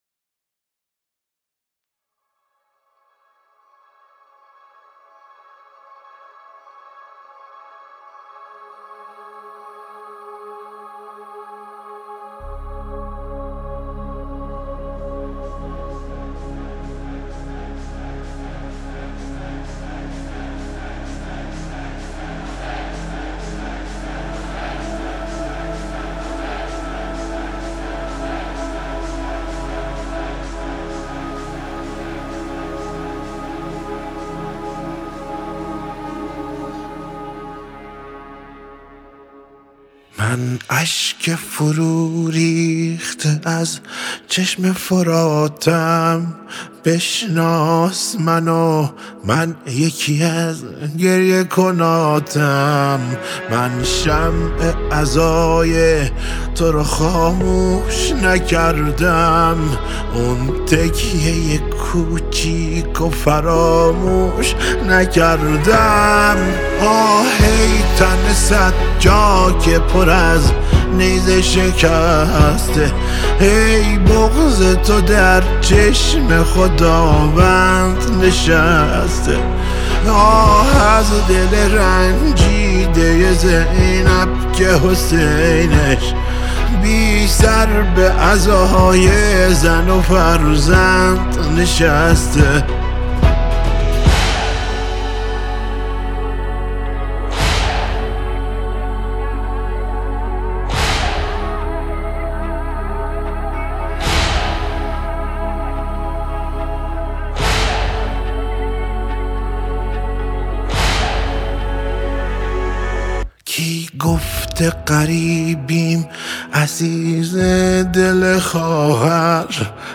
خواننده موسیقی پاپ